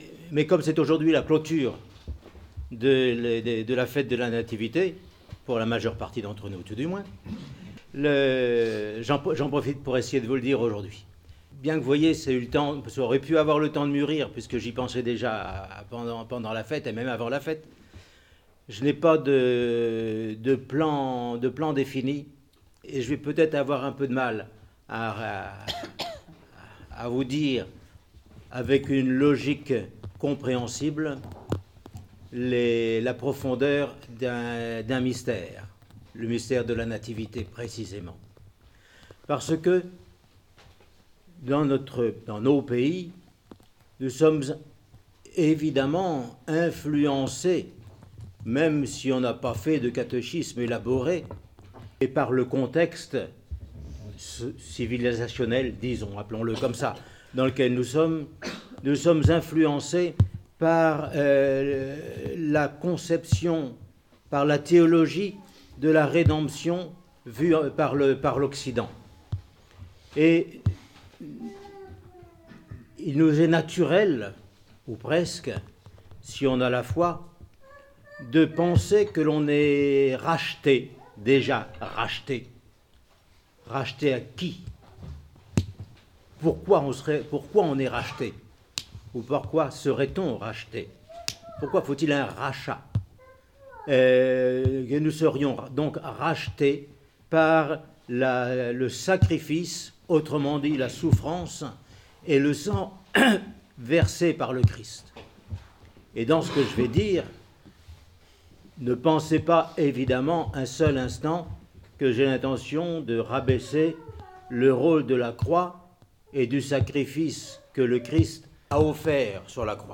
Homélie sur les conséquences de l’incarnation du Christ :Monastère de la Transfiguration